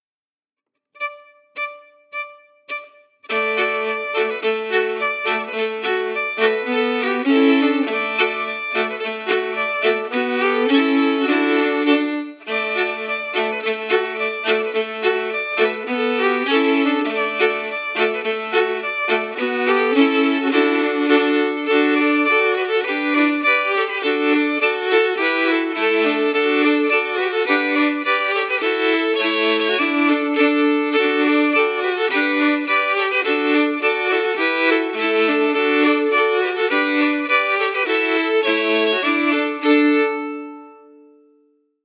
Key: D
Form: Polka